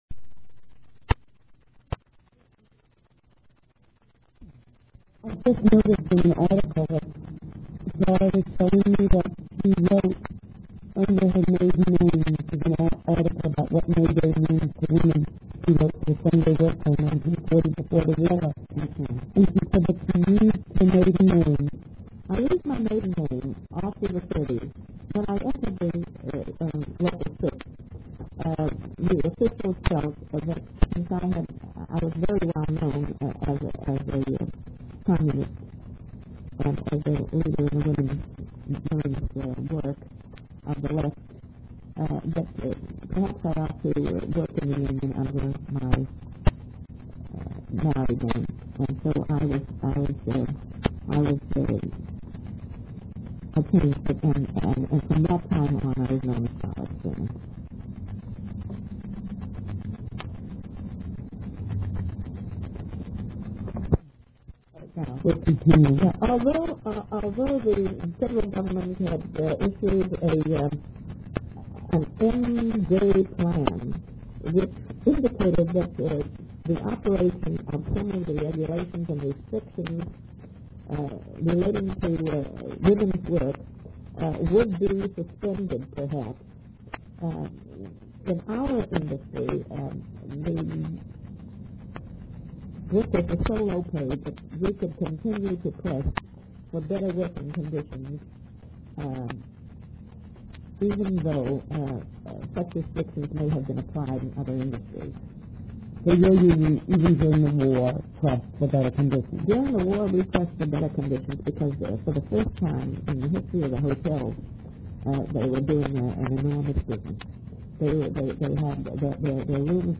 The interviewer noted that she found this session a bit chaotic. Although she couldn't quite put her finger on the problem, she surmised that both she and the narrator were easily sidetracked.